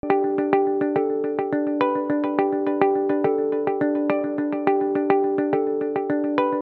Уменя есть электроакустика на ней можно так играть? какие эффекты кроме delay нужны?